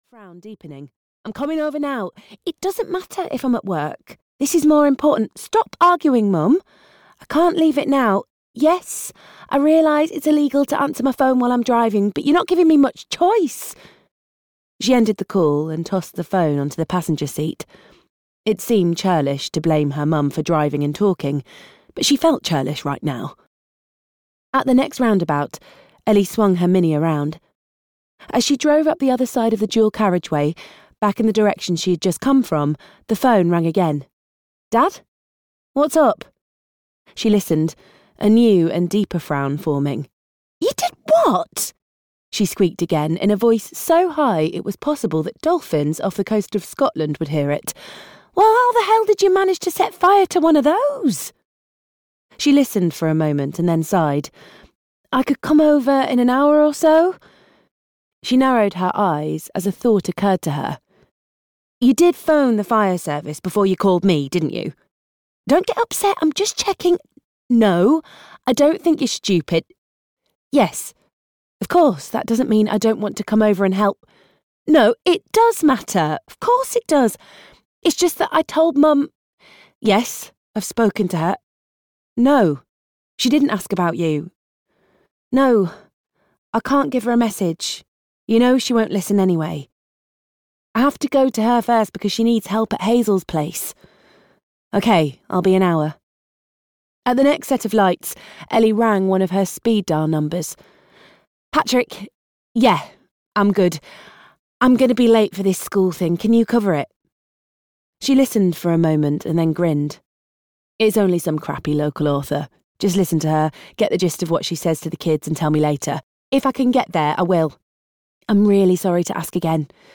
Audio knihaWorth Waiting For (EN)
Ukázka z knihy